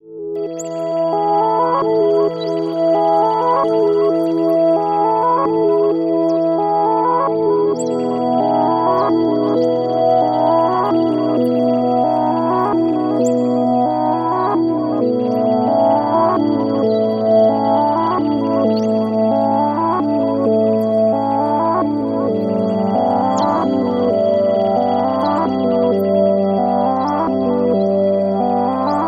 Tag: 66 bpm Ambient Loops Pad Loops 4.90 MB wav Key : E